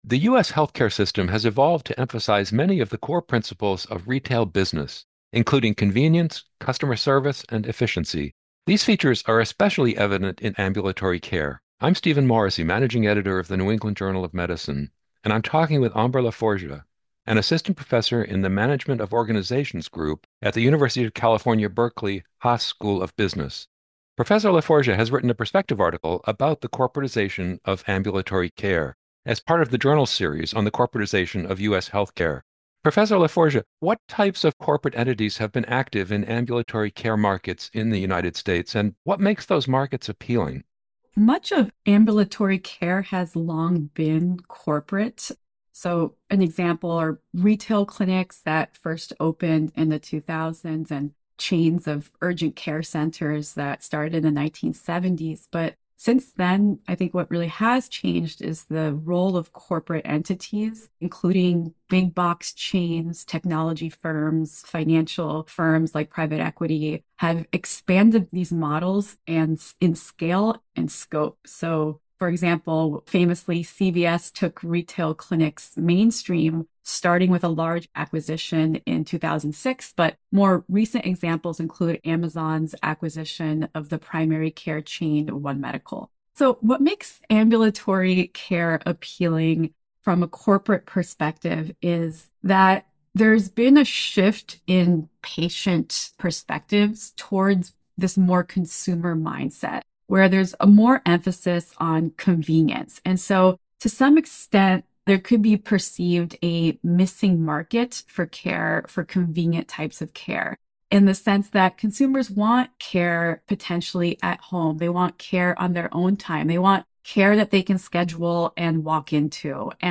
NEJM Interview